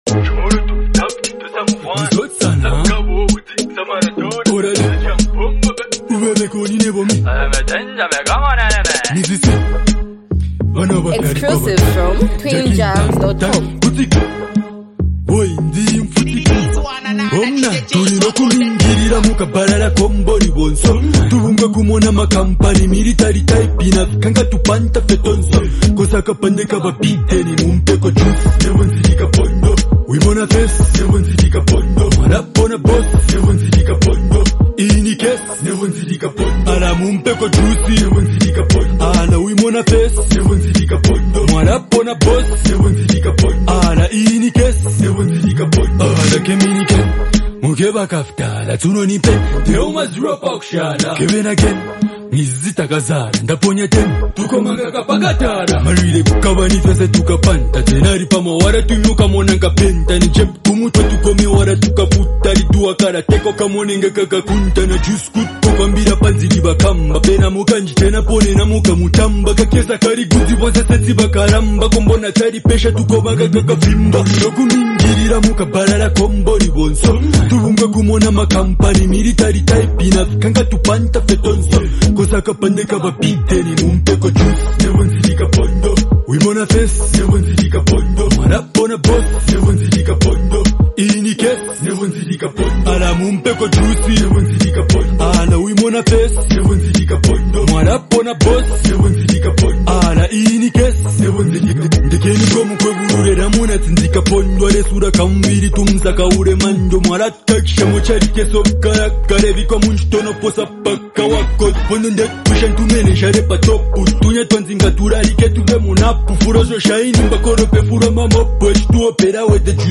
hype, street vibes, and a powerful hook with unique rap flow